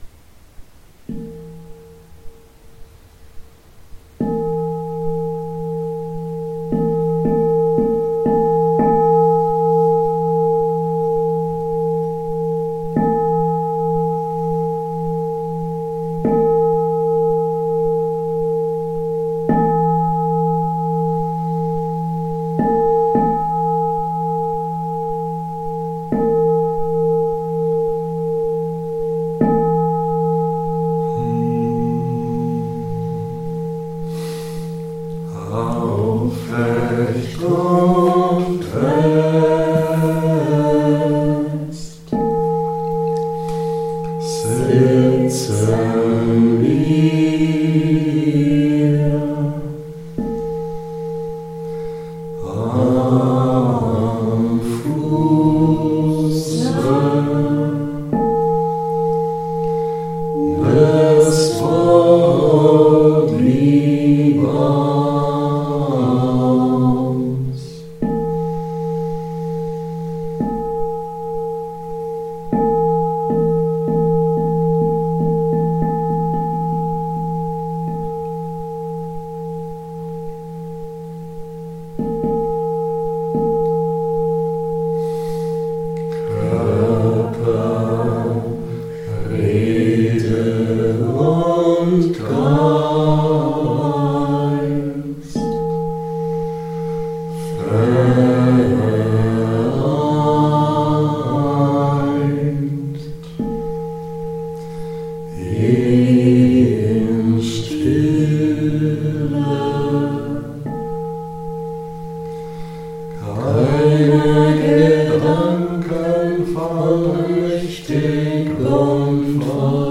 Es ist ein schöner Brauch, vor der Morgen- und Abendmeditation den Morgen- bzw. Abendvers zu singen. Das sind nicht nur schöne Lieder, sondern die Texte stimmen uns auch auf die Meditation ein.